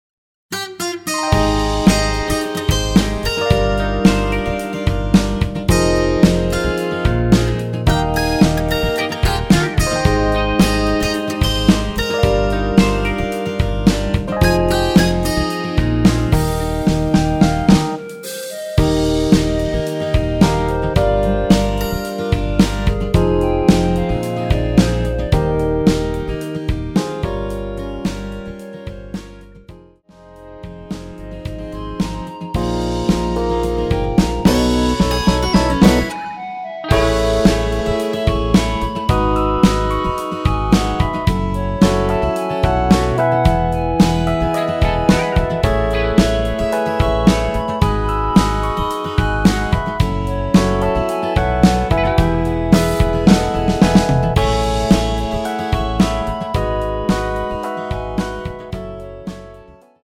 여자키 멜로디 포함된 MR 입니다.(미리듣기 참조)
Eb
앞부분30초, 뒷부분30초씩 편집해서 올려 드리고 있습니다.
중간에 음이 끈어지고 다시 나오는 이유는